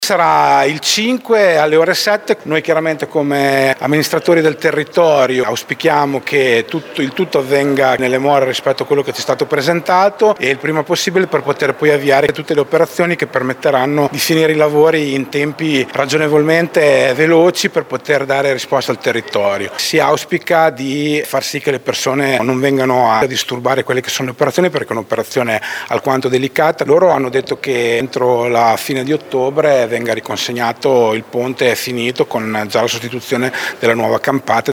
La data è stata annunciata ieri pomeriggio durante il summit in prefettura.
Il presidente della provincia Fabio Braglia: